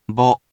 We’re going to show you the character, then you you can click the play button to hear QUIZBO™ sound it out for you.
In romaji, 「ぼ」 is transliterated as 「bo」which sounds sort of like the English word 「bow」as in what one would wear in their hair or put on a gift, just without the extra diphthong.